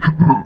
spawners_mobs_mummy_hit.3.ogg